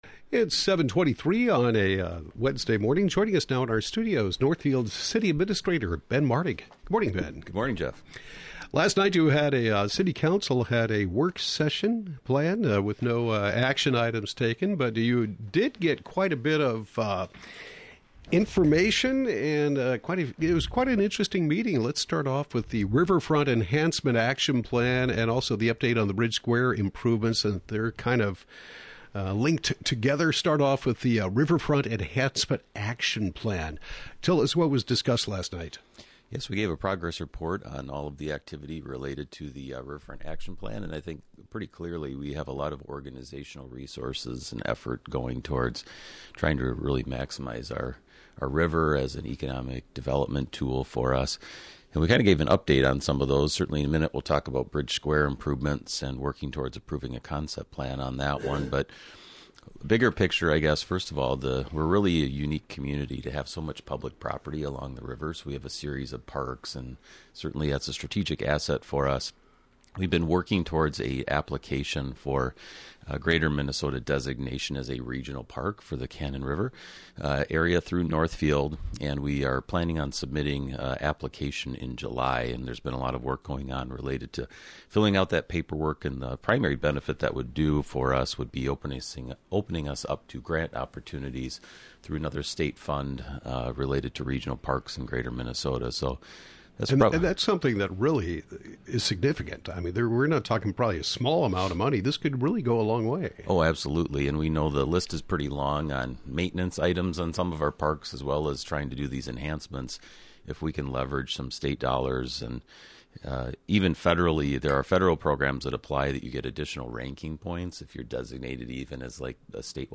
Northfield City Administrator Ben Martig discusses the June 14 City Council work session. Topics include the Riverfront Enhancement Plan, Bridge Square concept plan, redevelopment project on Fifth and Water Streets, comprehensive financial report and more.